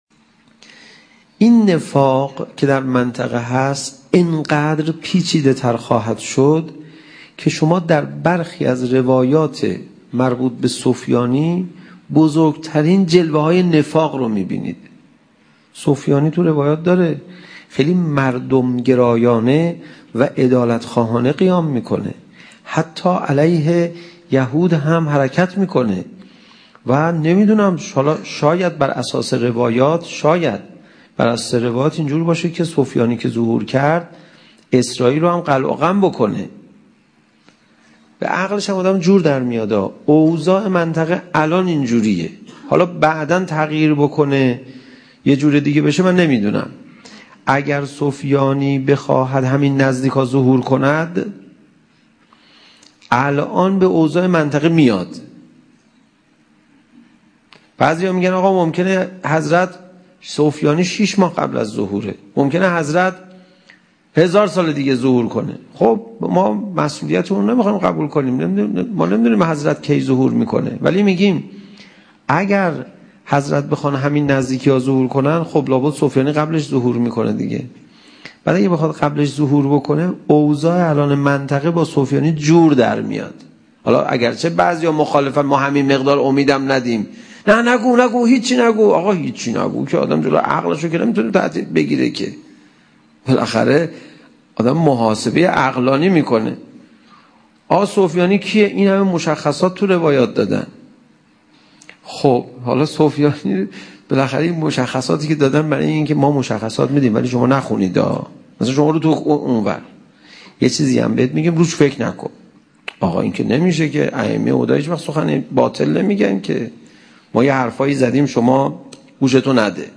سخنرانی حجت السلام پناهیان در مورد سفیانی